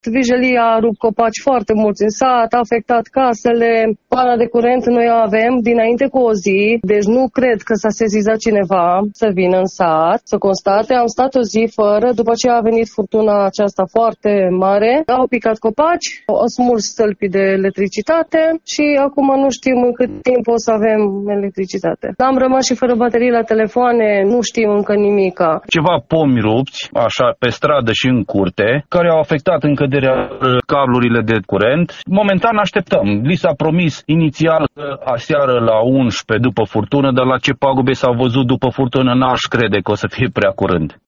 Vox-Dragsina.mp3